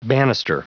Prononciation du mot banister en anglais (fichier audio)
Prononciation du mot : banister